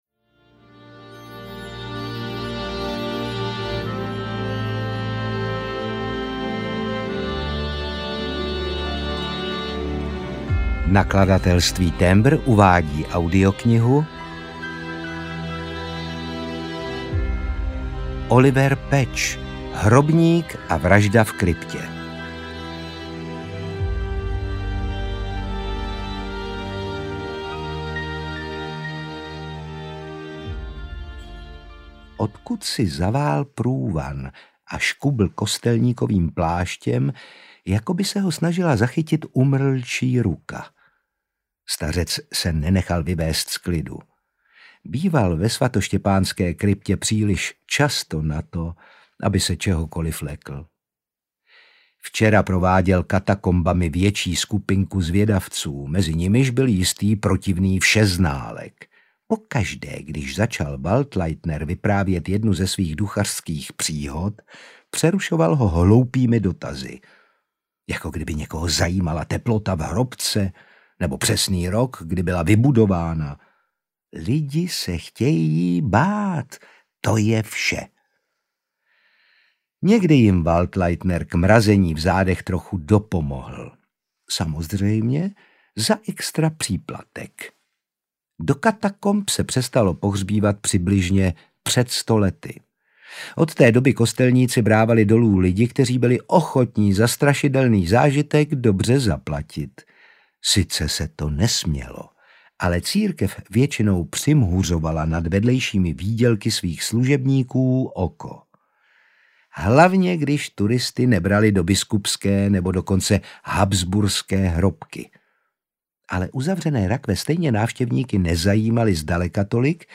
Hrobník a vražda v kryptě audiokniha
Ukázka z knihy
• InterpretOtakar Brousek ml.